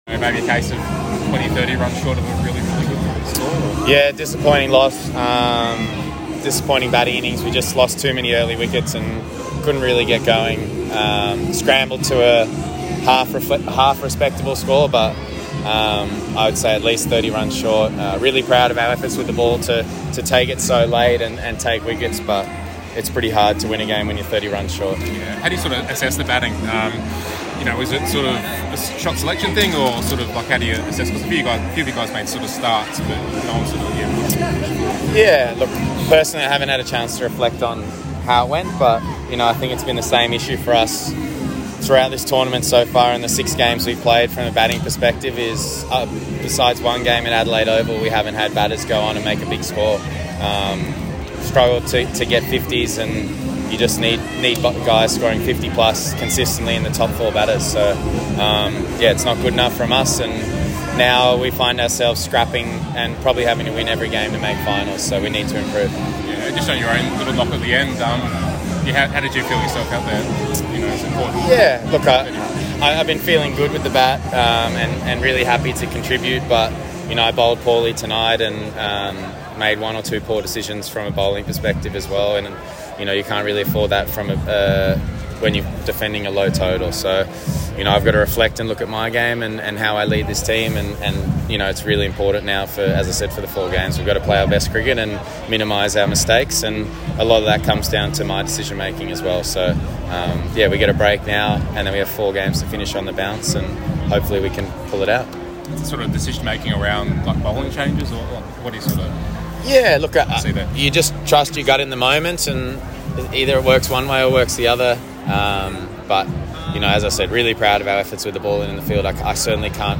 Sydney Thunder captain Chris Green after Thunder’s 7 wicket loss to Hobart Hurricanes (BBL13)